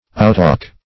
Outtalk \Out*talk"\, v. t.